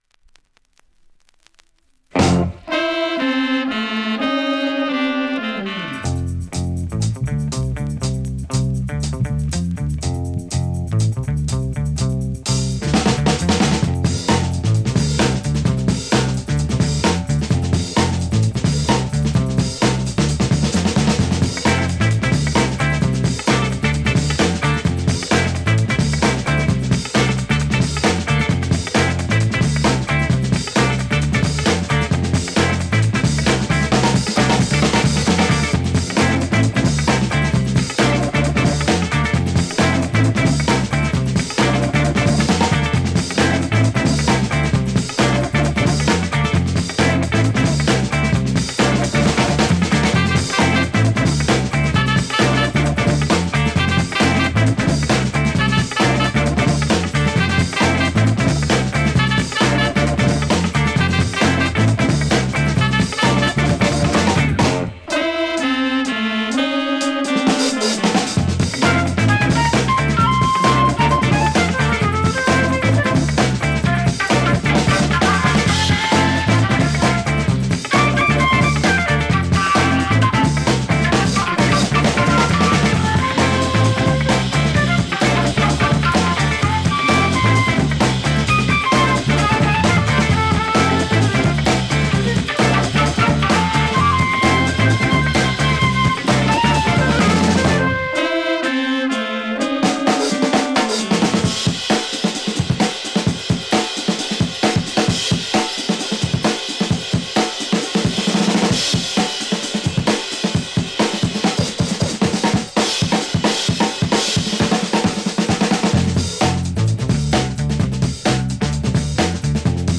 現物の試聴（両面すべて録音時間７分３８秒）できます。